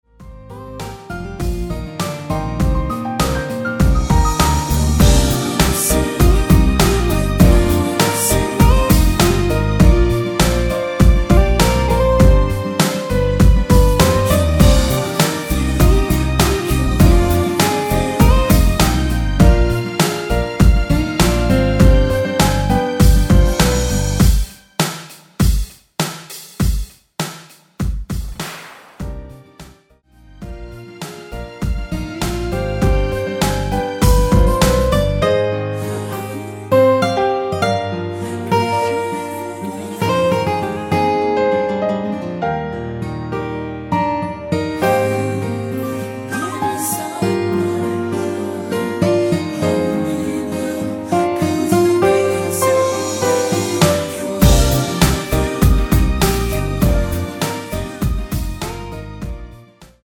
원키에서(-1)내린 코러스 포함된 MR 입니다.(미리듣기 참조)
Cm
앞부분30초, 뒷부분30초씩 편집해서 올려 드리고 있습니다.
중간에 음이 끈어지고 다시 나오는 이유는